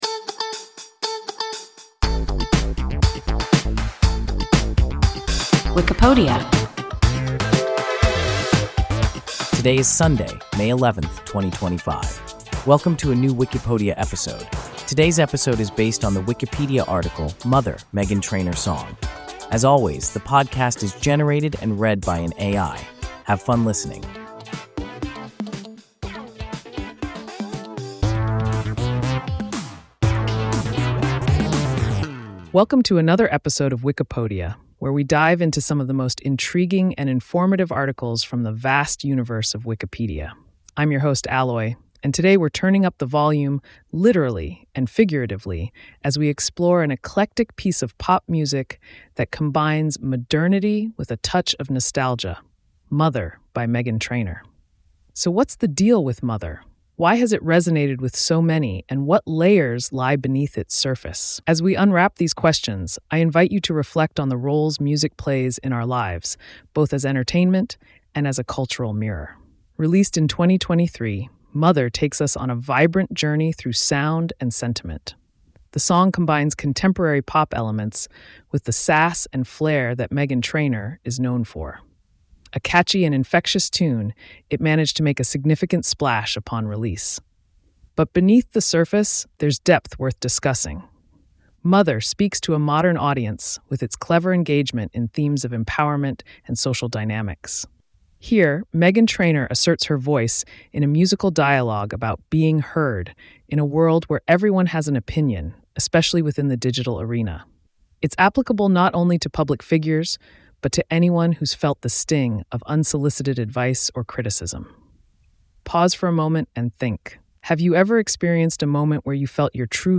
Mother (Meghan Trainor song) – WIKIPODIA – ein KI Podcast